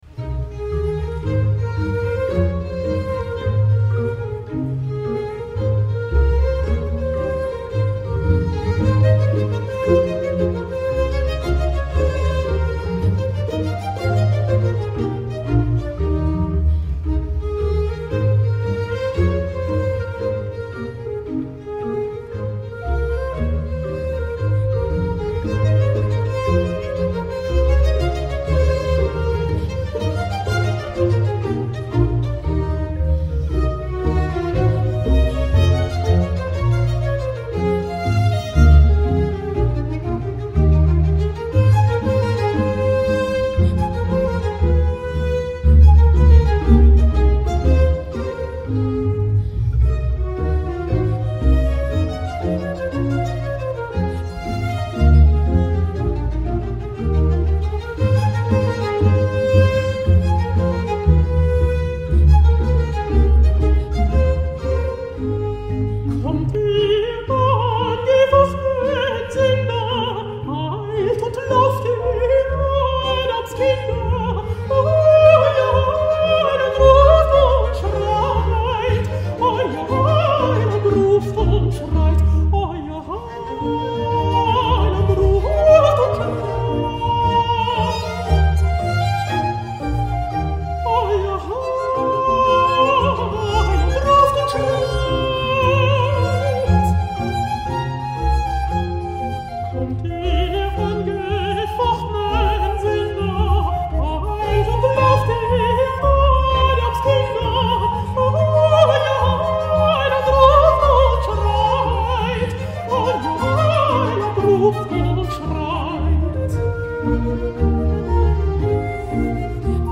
BACH, cantate bwv 30, aria sop - VERONESE, scene de campagne.mp3